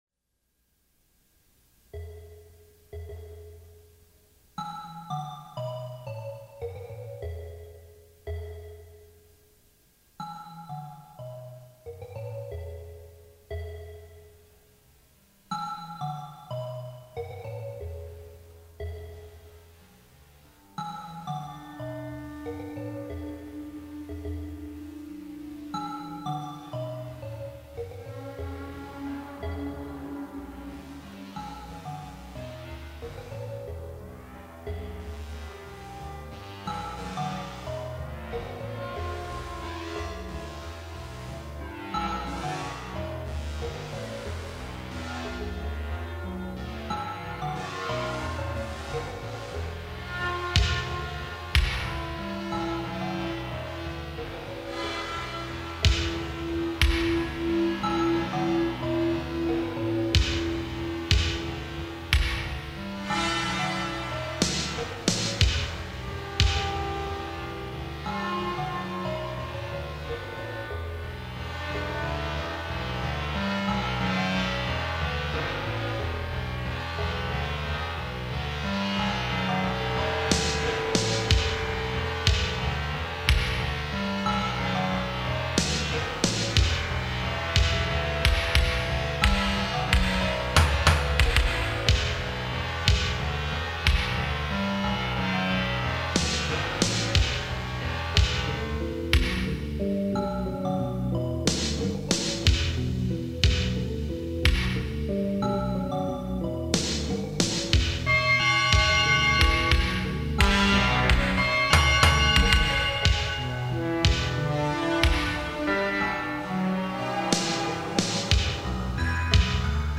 guitar
a Kawai R-100 drum machine